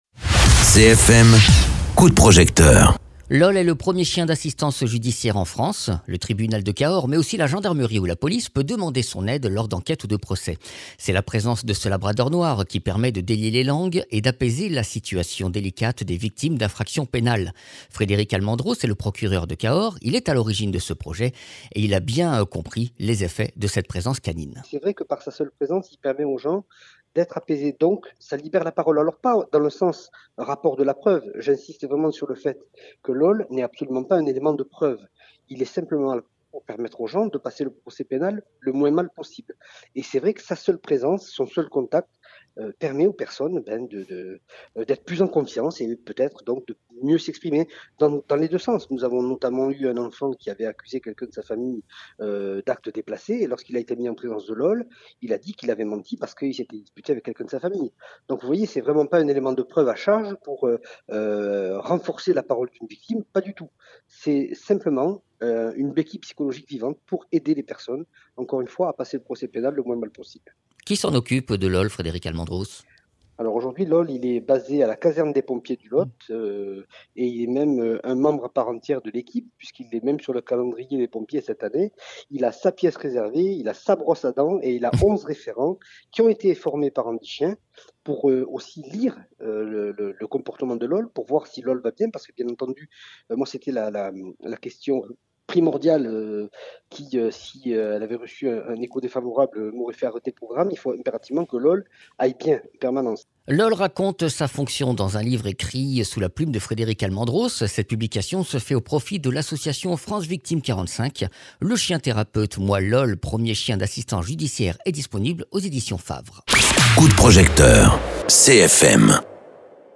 Interviews
Invité(s) : Frederic Almendros - Procureur au tribunal de Cahors